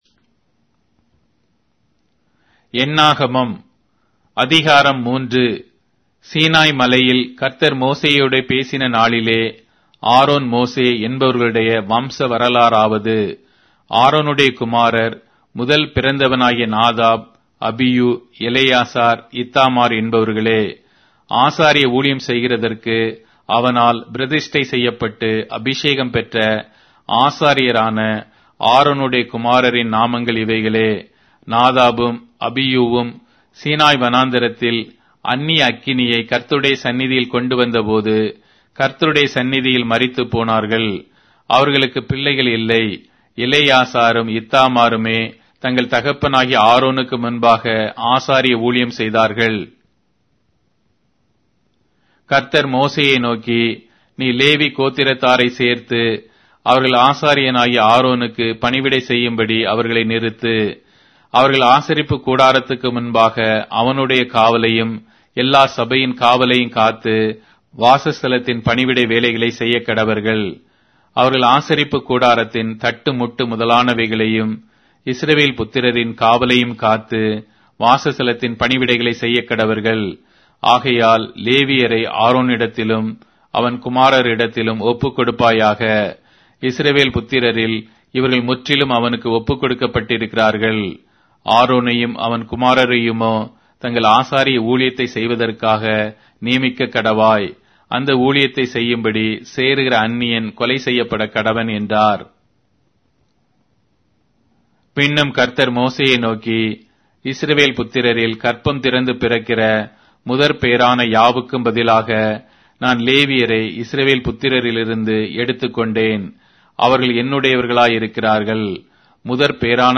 Tamil Audio Bible - Numbers 21 in Litv bible version